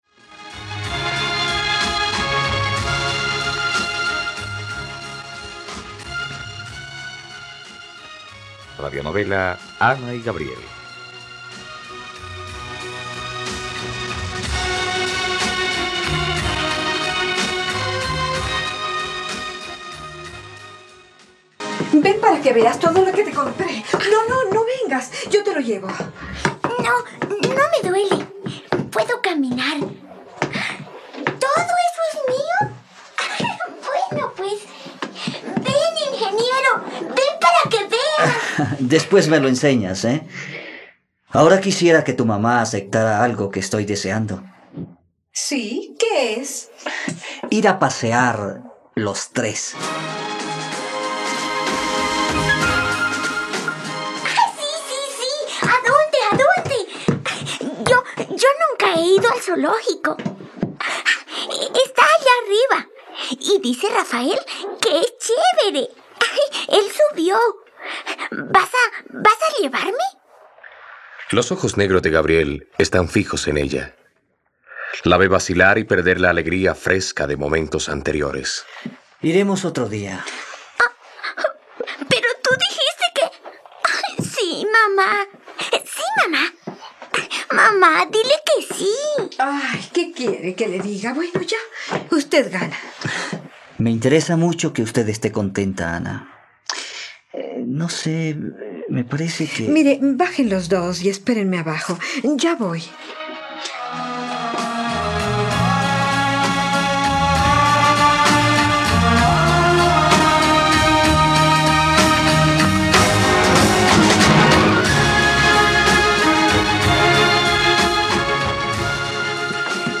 ..Radionovela. Escucha ahora el capítulo 24 de la historia de amor de Ana y Gabriel en la plataforma de streaming de los colombianos: RTVCPlay.